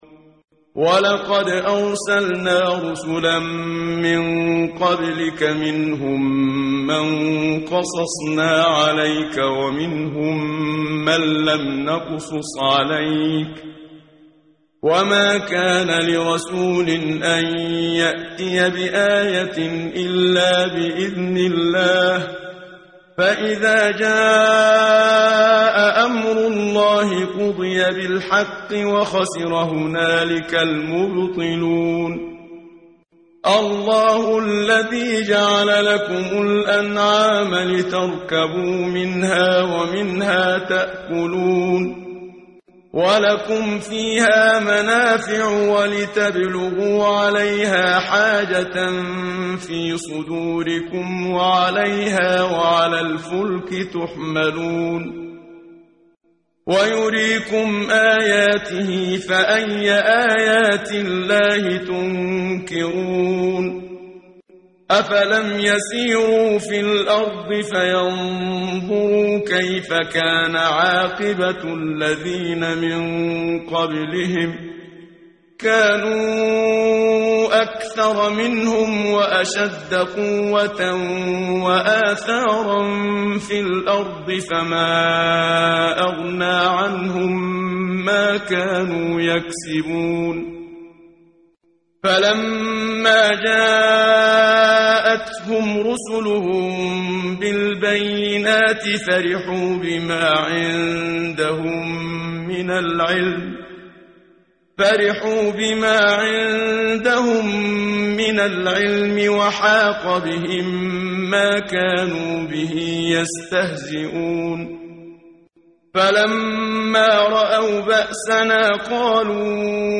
قرائت قرآن کریم ، صفحه 476، سوره مبارکه «غافر» آیه 78 تا 85 با صدای استاد صدیق منشاوی.